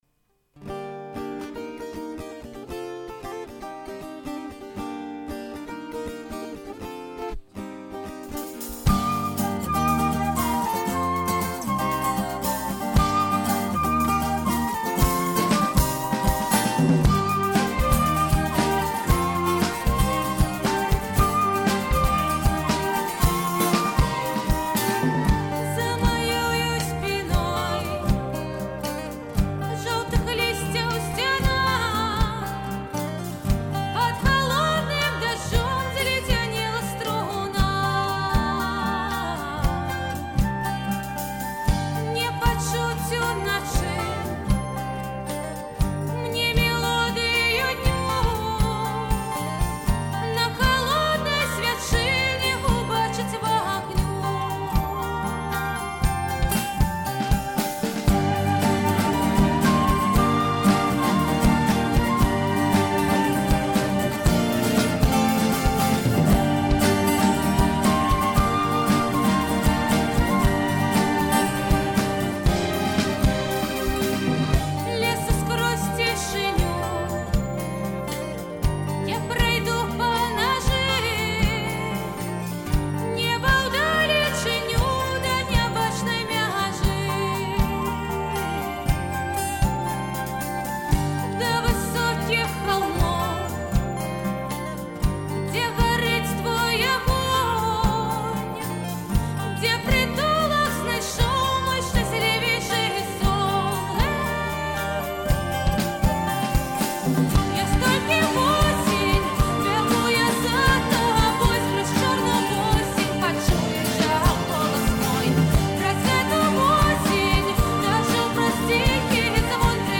Стыль - нэафольк, акустычны фольк.